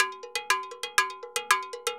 Timbaleta_Salsa 120_4.wav